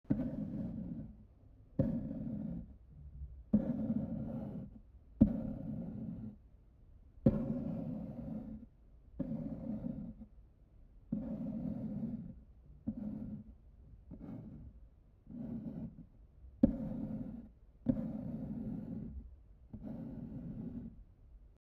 clawsOnWood.m4a